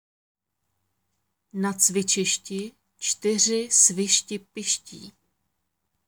Děkuji za zájem o nahrávku výslovnost C, Č, S, Š
Tady si můžete stáhnout audio na výslovnost C, Č, S a Š: Na cvičišti čtyři svišti piští